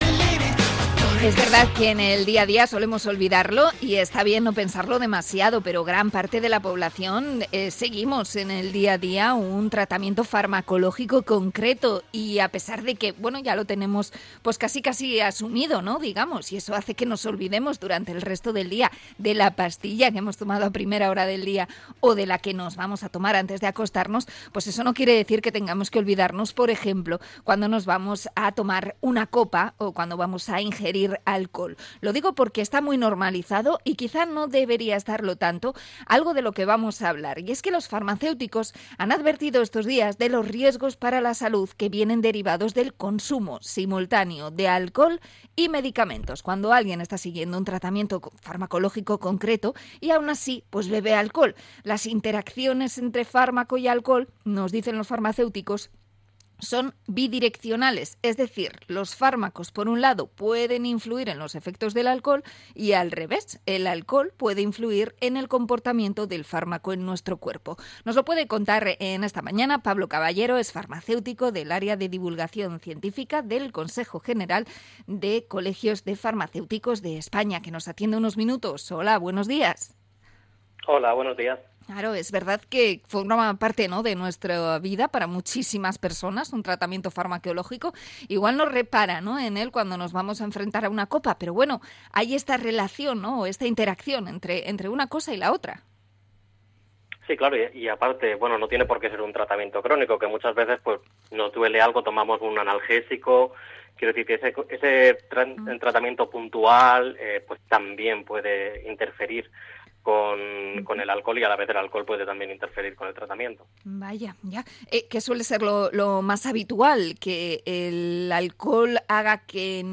Entrevista a farmacéutico sobre mezclar medicamentos y alcohol